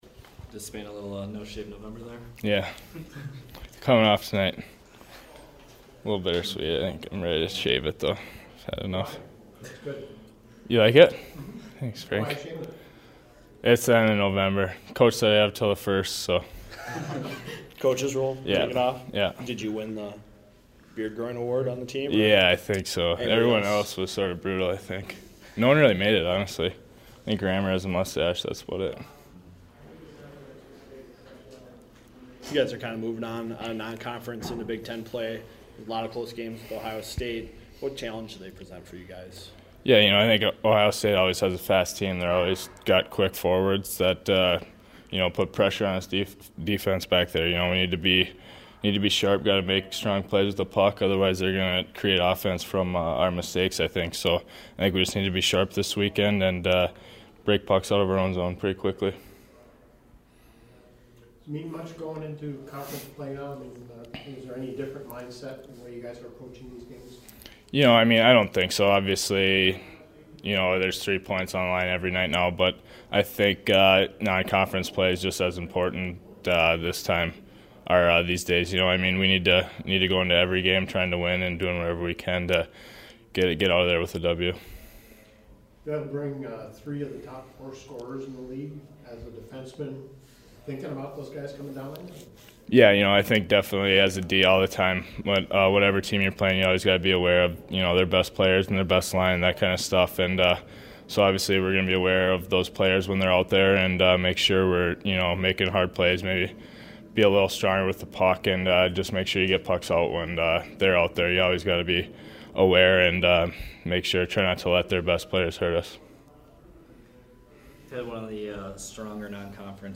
Media Availability